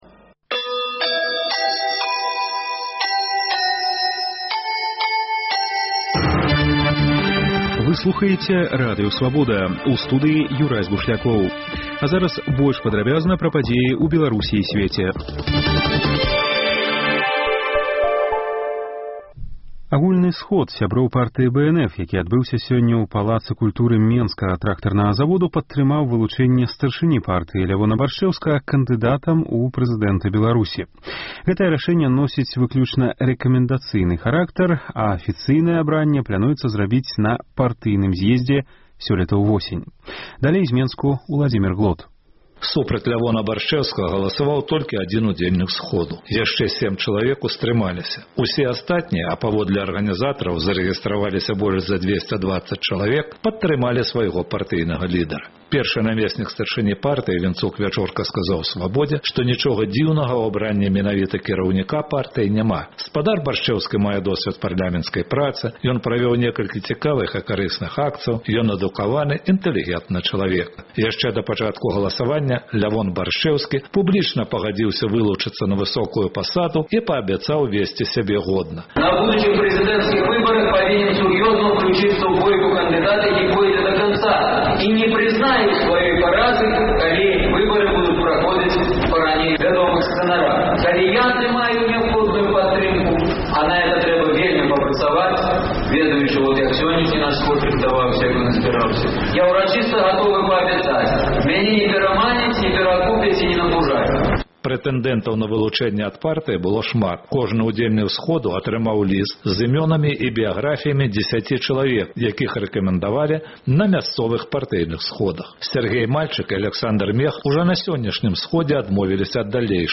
Інфармацыйны блёк: навіны Беларусі і сьвету